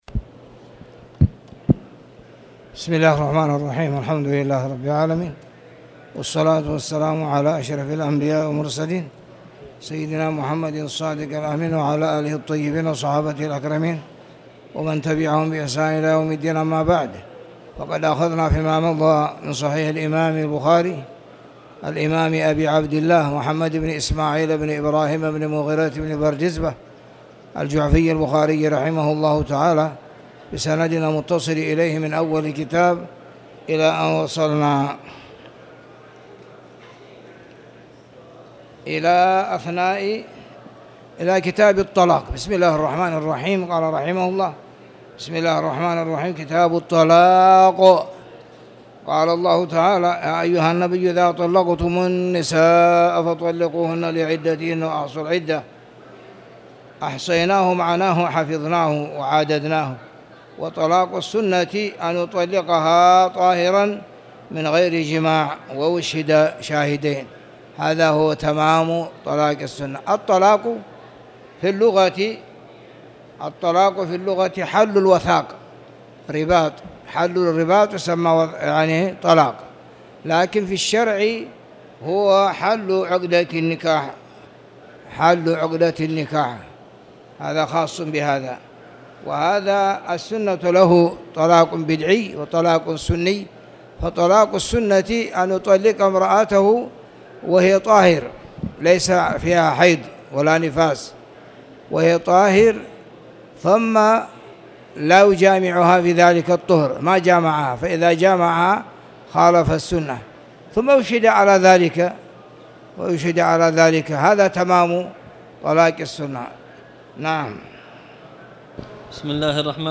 تاريخ النشر ٢٣ ذو الحجة ١٤٣٨ هـ المكان: المسجد الحرام الشيخ: فضيلة الشيخ محمد بن علي آدم الأتيوبي فضيلة الشيخ محمد بن علي آدم الأتيوبي كتاب الطلاق